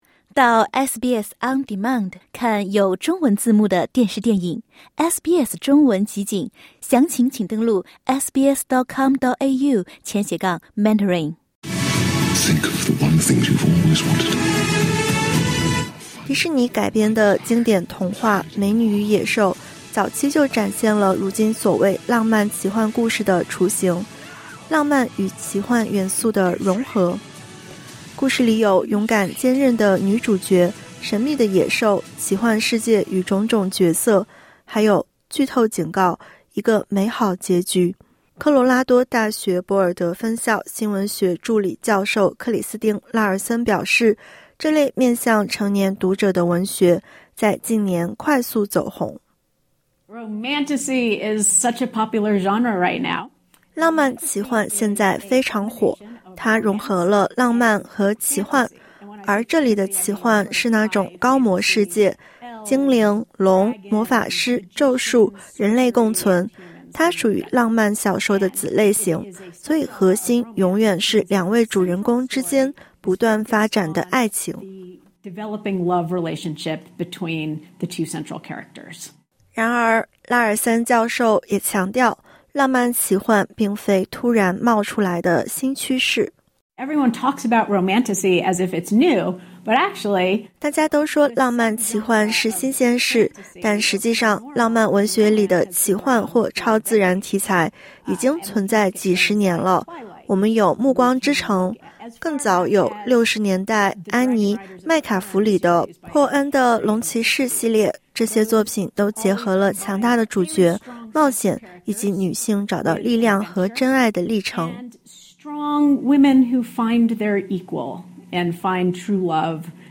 强大女性主角让新世代读者深受吸引 (点击音频收听详细报道)。